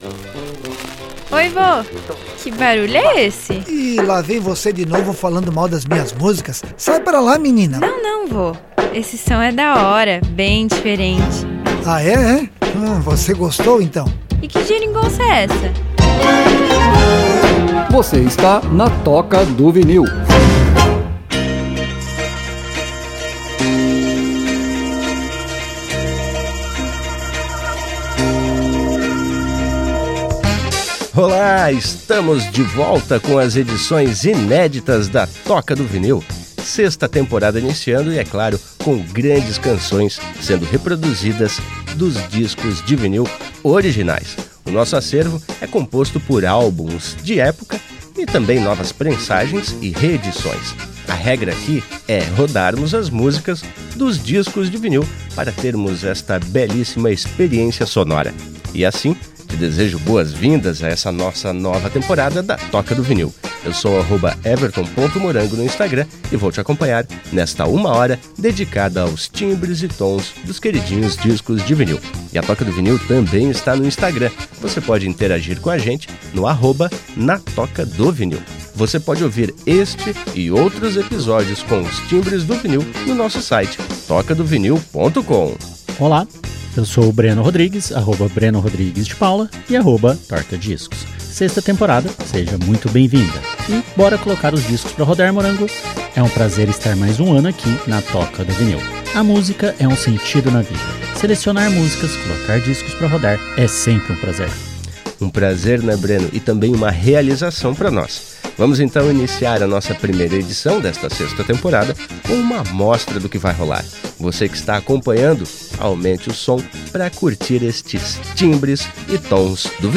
Para esta edição preparamos uma seleção que une o novo e o clássico, mostrando a força da música em diferentes tempos e estilos, tudo direto do vinil, com a nitidez e a definição que só o analógico oferece.